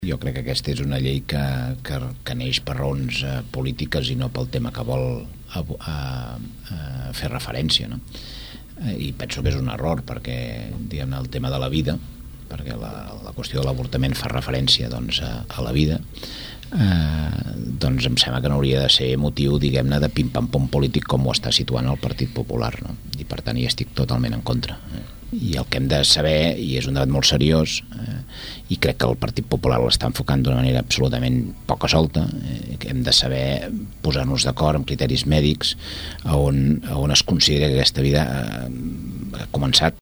Francesc Homs, entrevistat a \'L\'hora del xumet\'.